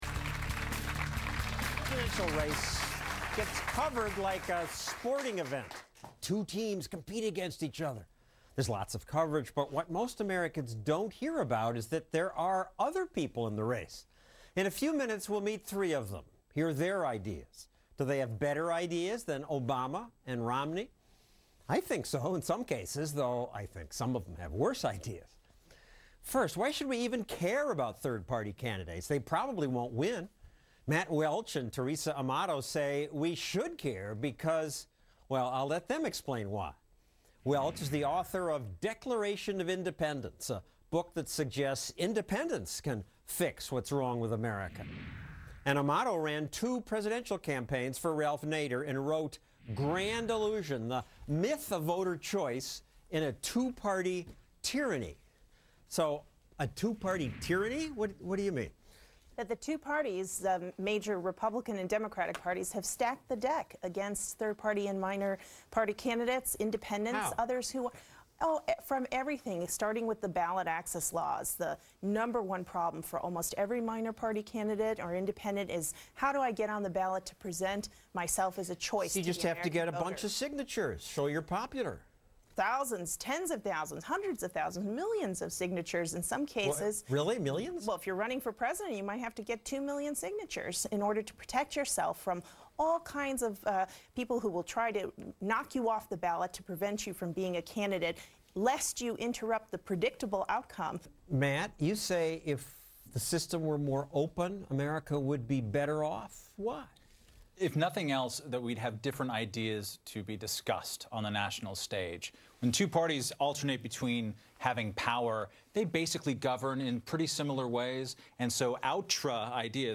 speaking on Stossel, September 13, 2012.